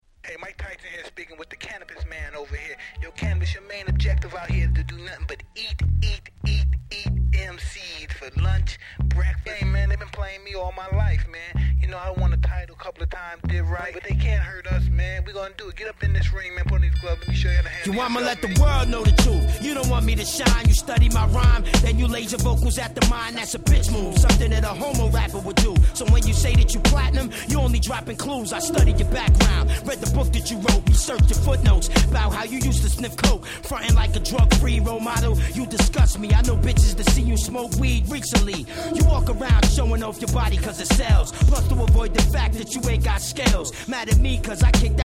90's Hip Hop Classic !!
90's Boom Bap ブーンバップ